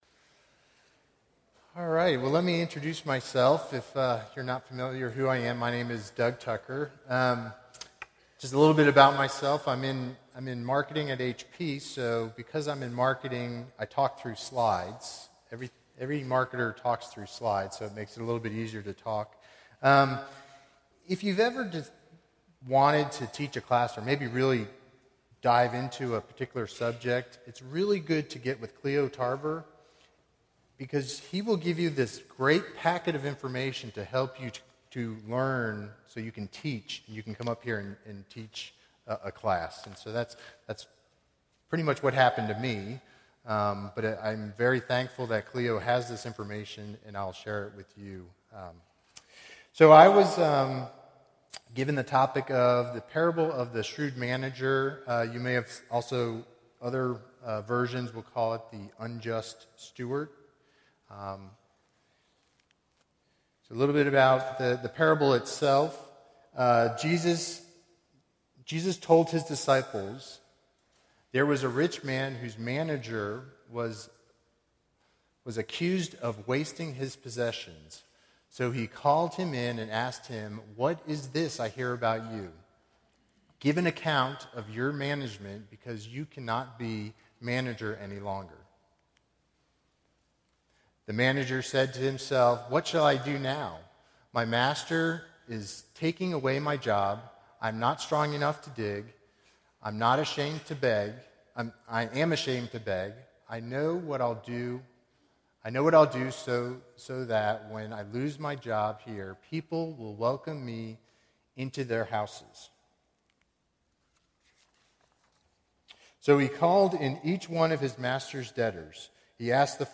A Study of Selected Parables (5 of 7) – Bible Lesson Recording